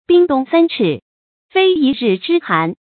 冰凍三尺，非一日之寒的讀法